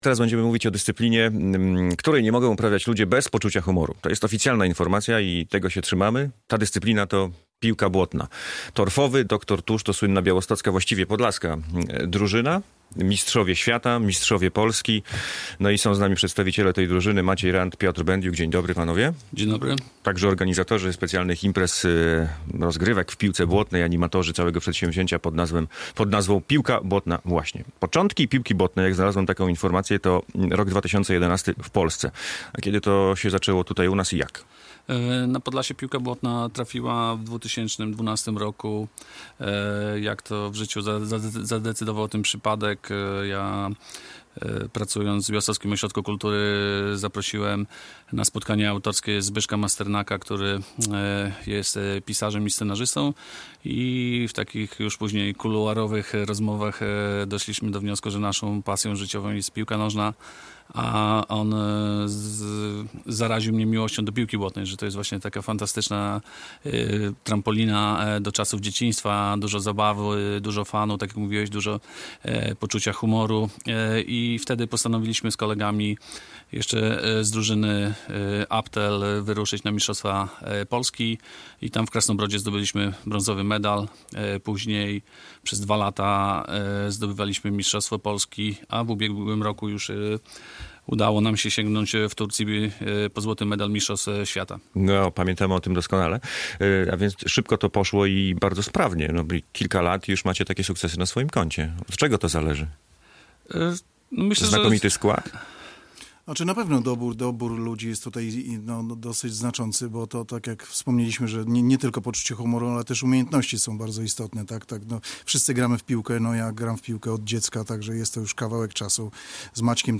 Radio Białystok | Gość
Piłka błotna - rozmowa z Torfowym Dr Tuszem | Pobierz plik.